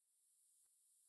Prayer and Worship Service
No sermon this week, as we gathered for an extended time of prayer and worship.